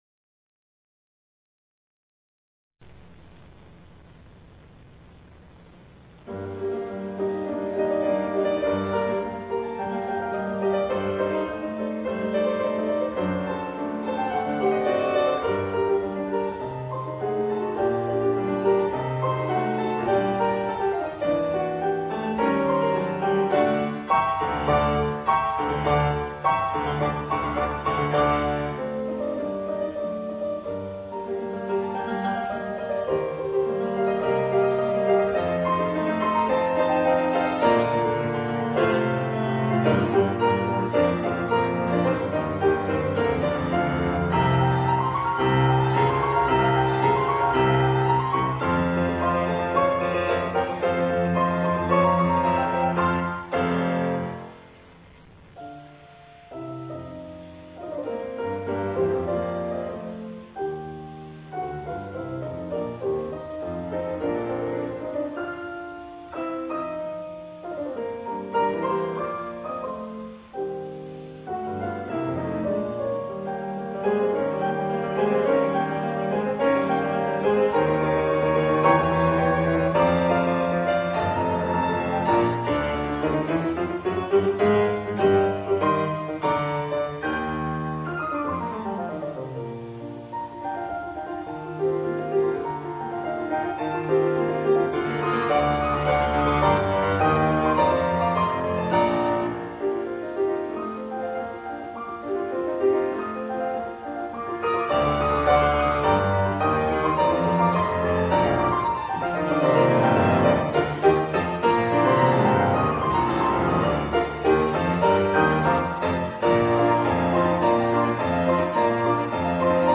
この演奏会は福井の「ユー・アイふくい」多目的ホールで行なわれました。
当日はトークを交えて楽しいコンサートとなりましたが、自編曲が私のピアノのテクニックを超えるハードだったため、体力的にも限界状態の演奏もあります。